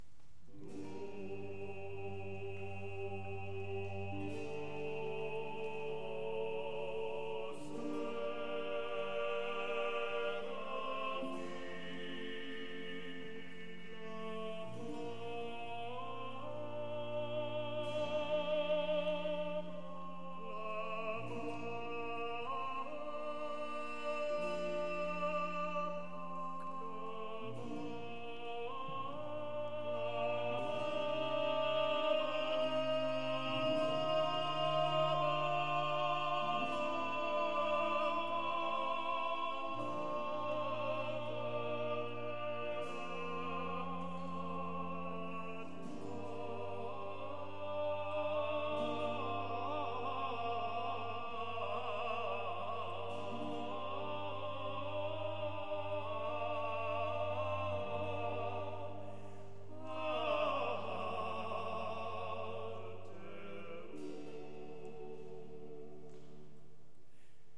Music of the Baroque Chorus and Instruments
The remaining choral movements consist of five psalm settings—“Dixit Dominus” (six-part choir), “Laudate pueri” (eight-part choir) , “Laetatus sum” (six-part choir), “Nisi Dominus” (ten-part choir), and “Lauda Jerusalem” (seven-part choir)—the hymn “Ave maris stella” (eight-part choir) , and the Magnificat (in these performances, the seven voice version).